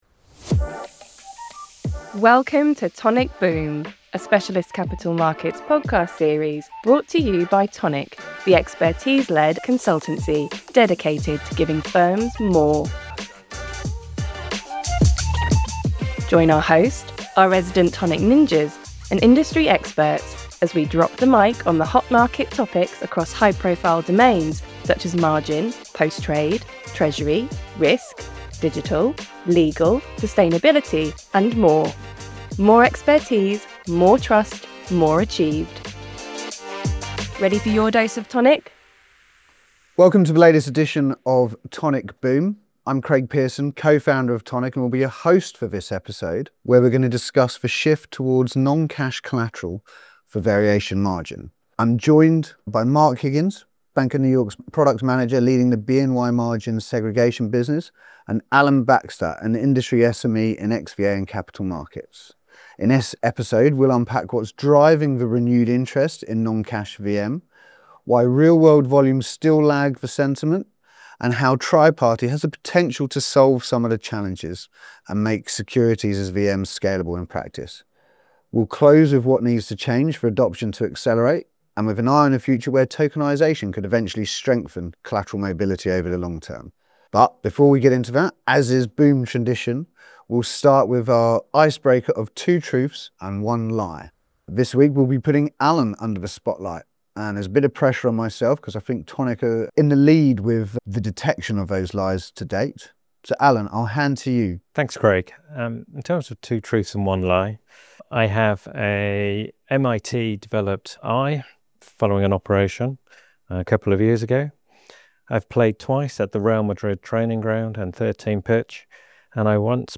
The conversation covers: